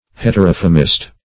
Heterophemist \Het`er*oph"e*mist\, n.
heterophemist.mp3